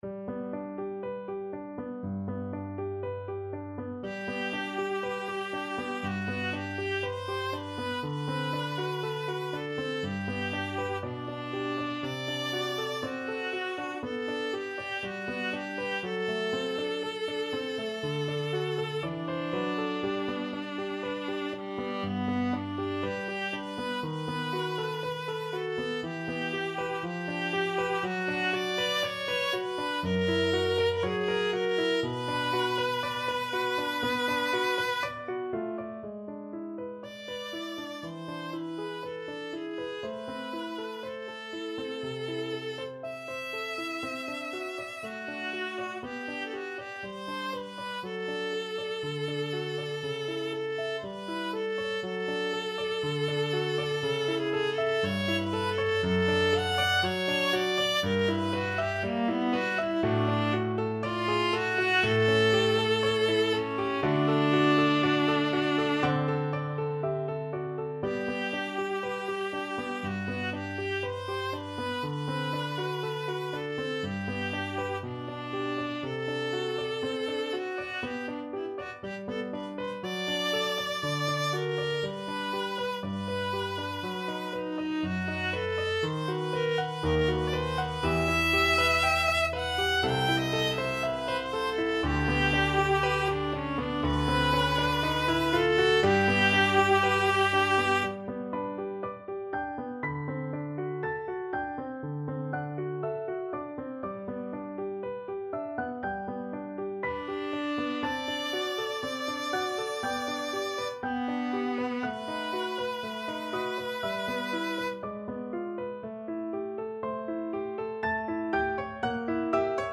4/4 (View more 4/4 Music)
~ = 100 Andante quasi Adagio
Classical (View more Classical Viola Music)